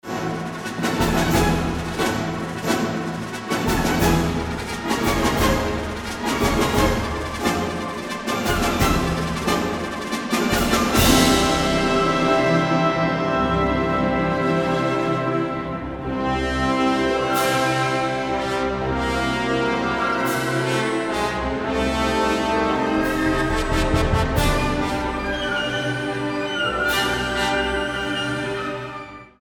A Concerto for Sarod, Concertante Group and String Orchestra